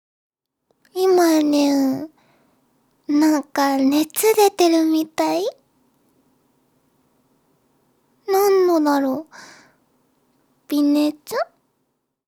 やさしい声は、いちばん効くおくすり💊
ボイスサンプル